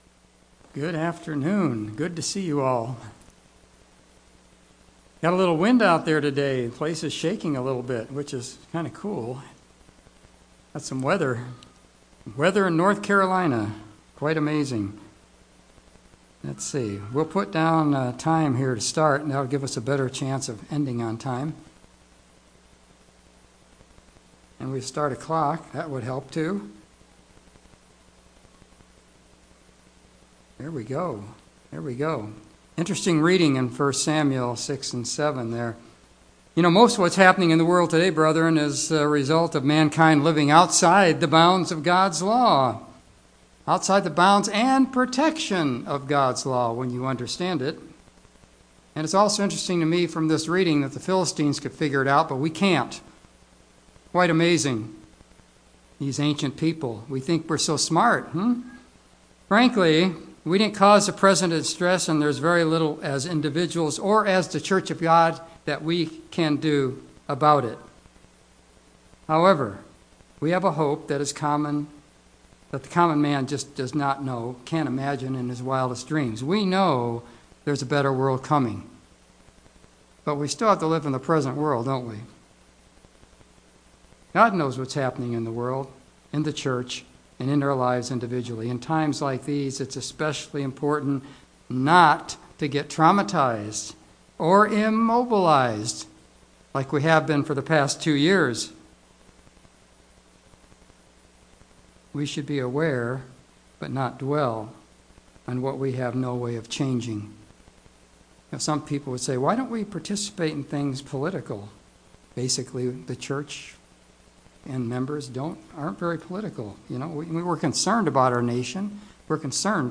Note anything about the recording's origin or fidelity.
Given in Greensboro, NC